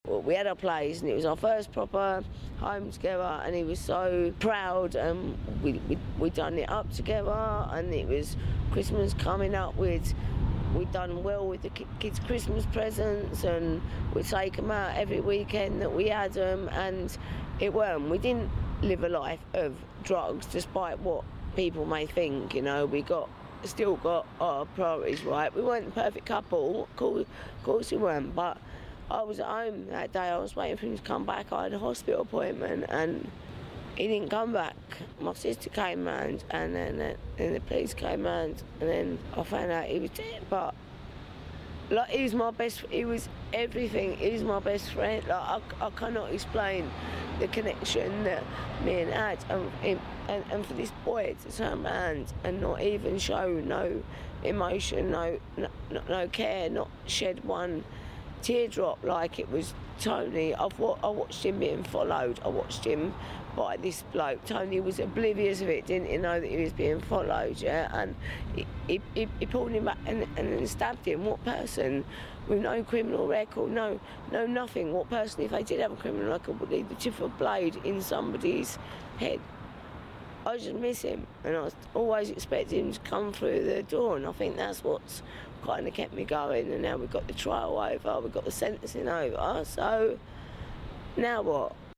speaks outside court